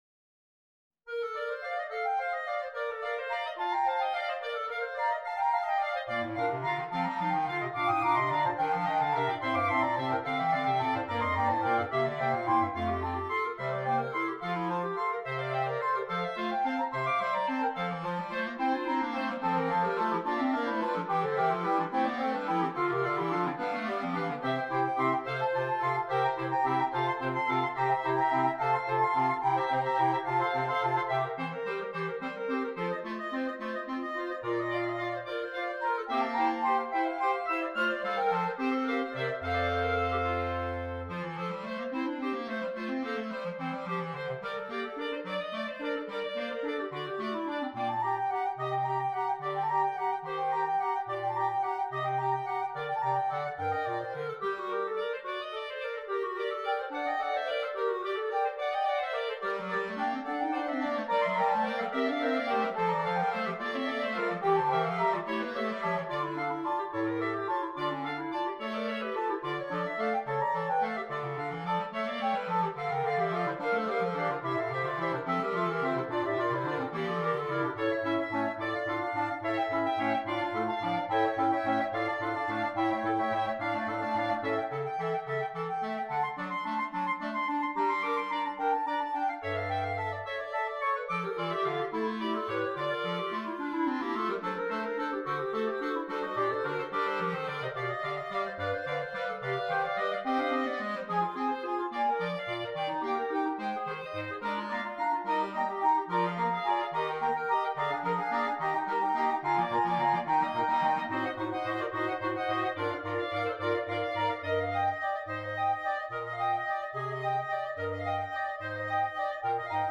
5 Clarinets, Bass Clarinet